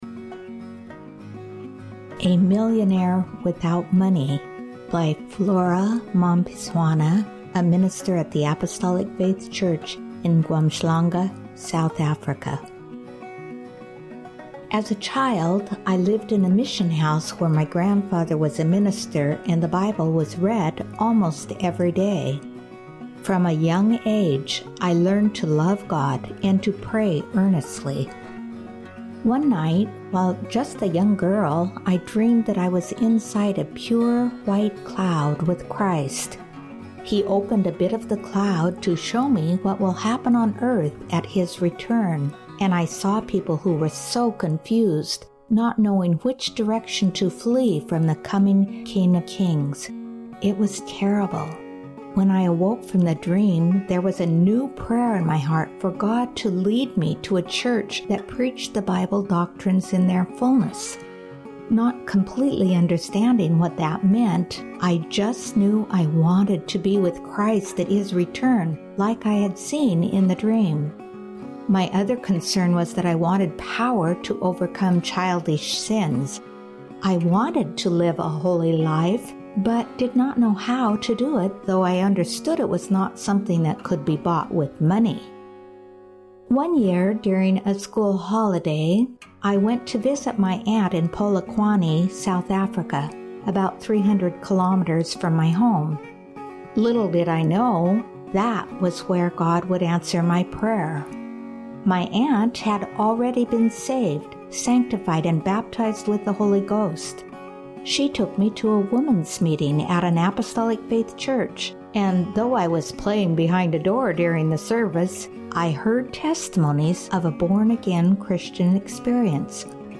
Witness